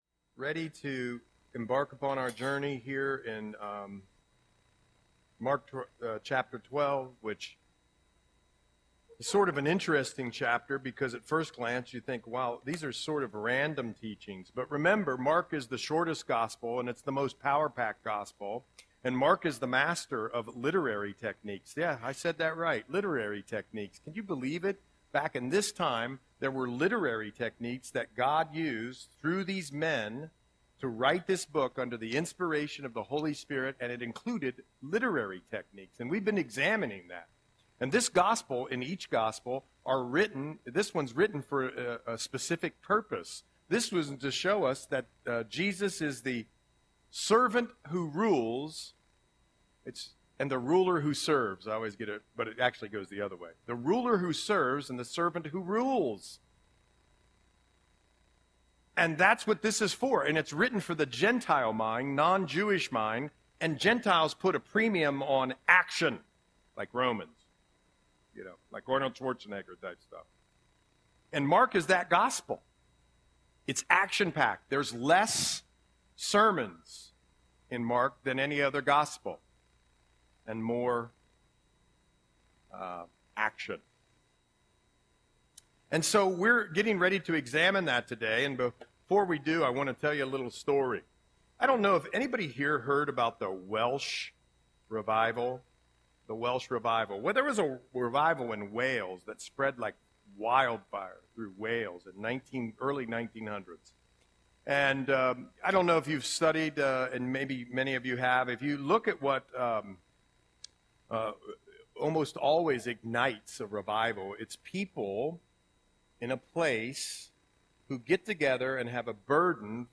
Audio Sermon - February 16, 2025